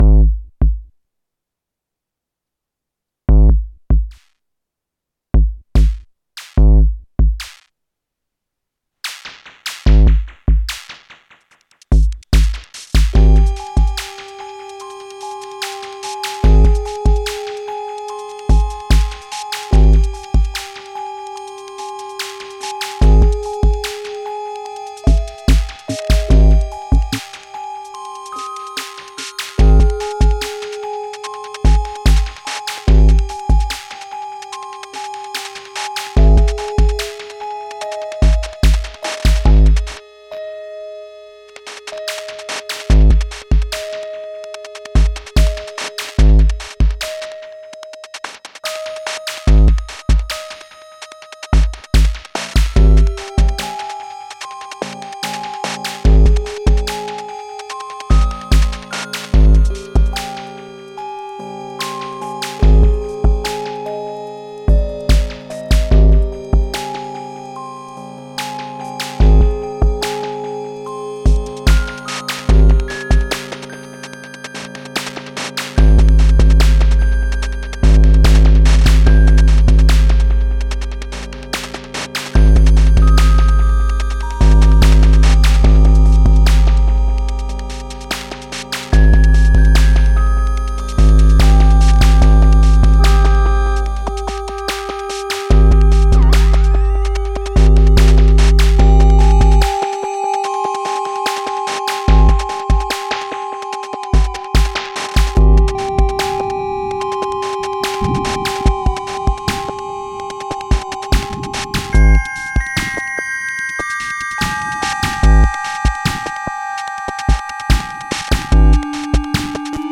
Some random shit. Sloppy but the promise is there for how I want to work.